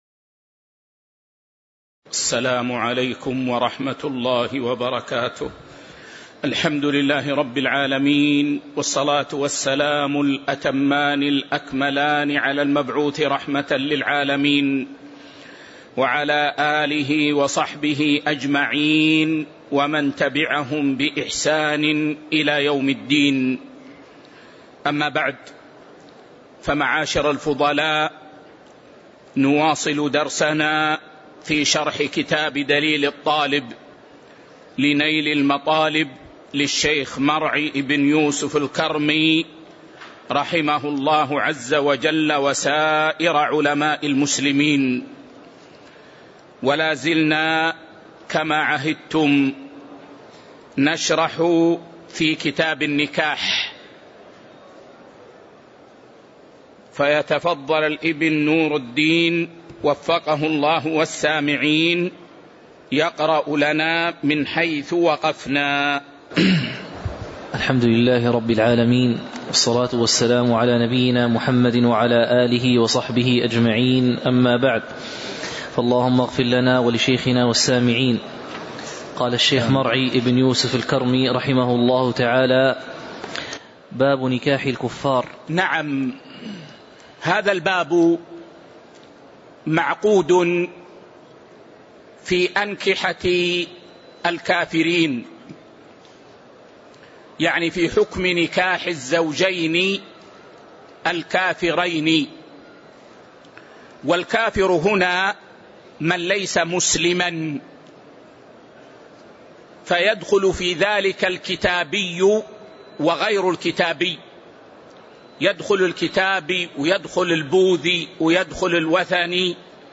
تاريخ النشر ٩ ربيع الأول ١٤٤٦ هـ المكان: المسجد النبوي الشيخ